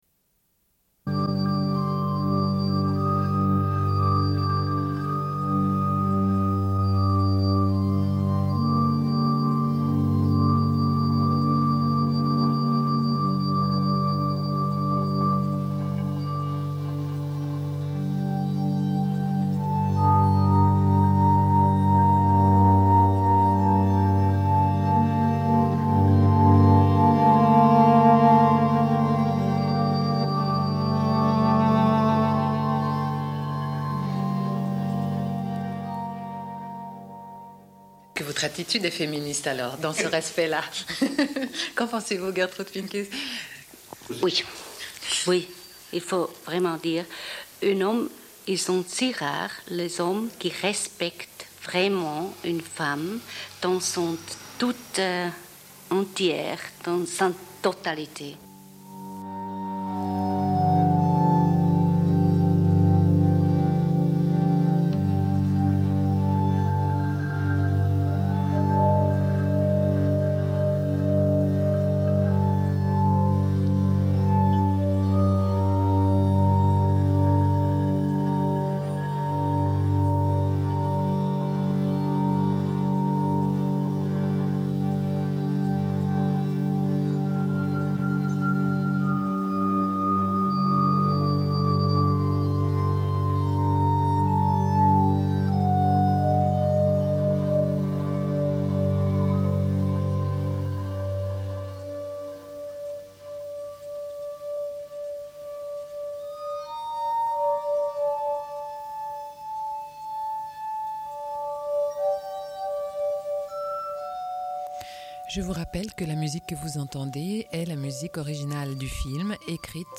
Une cassette audio, face B28:37
Suite de l'émission : diffusion d'un entretien